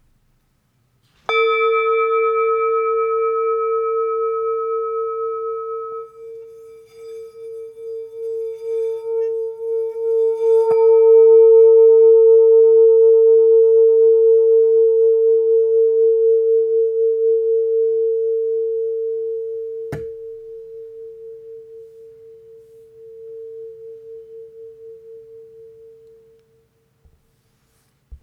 A Note 6″ Singing Bowl